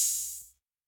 open hat_2.wav